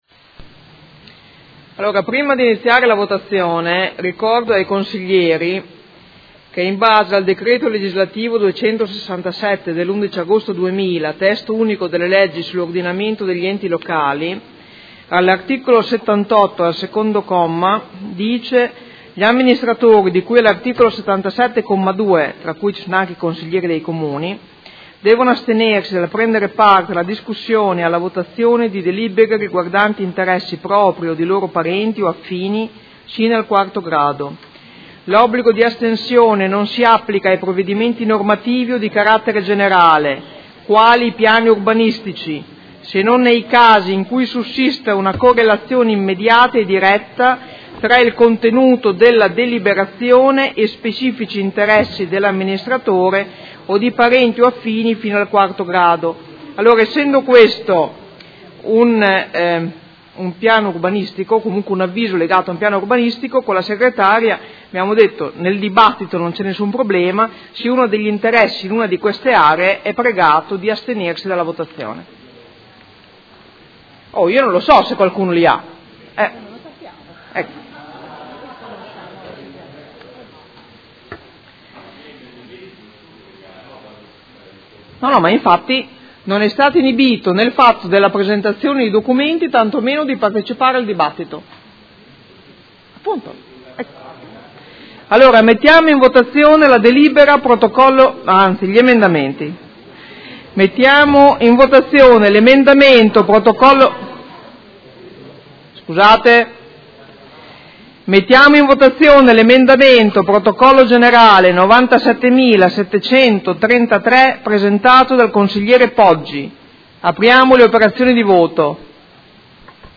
Presidente — Sito Audio Consiglio Comunale
Presidente
Seduta del 28/06/2018.